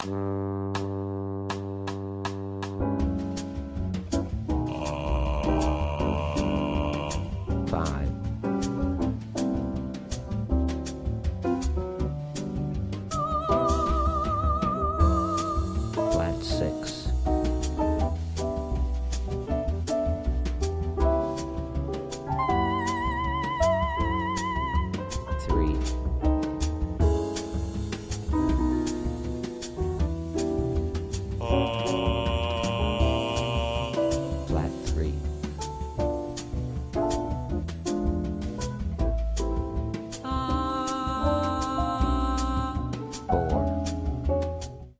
• Listening Exercise at Fast Tempo